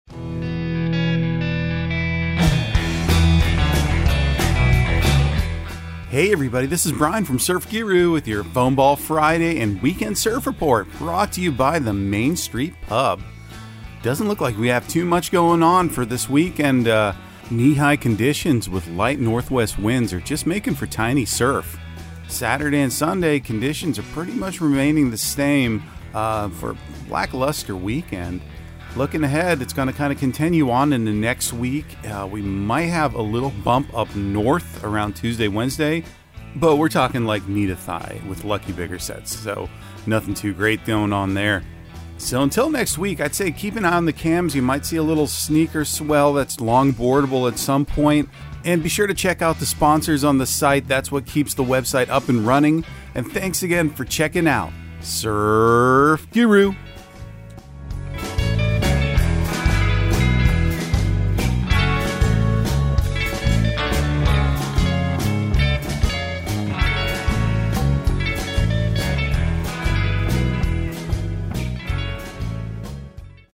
Surf Guru Surf Report and Forecast 01/06/2023 Audio surf report and surf forecast on January 06 for Central Florida and the Southeast.